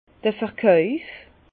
Bas Rhin d'r Verkàuf